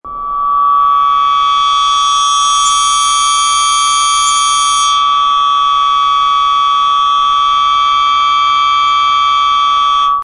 High Frequency On Humans